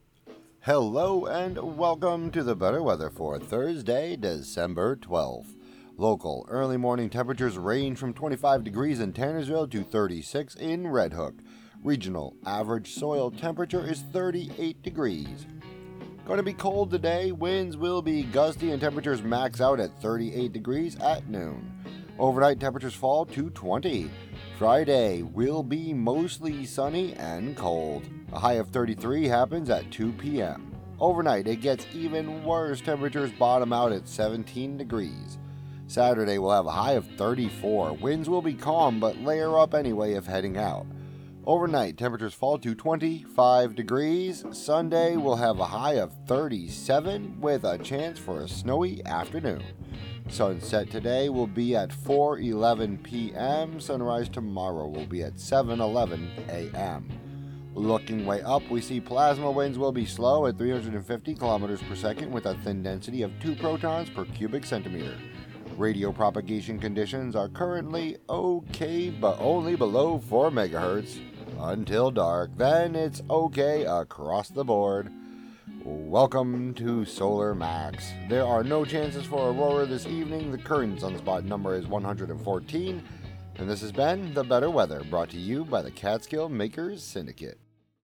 and more on WGXC 90.7-FM.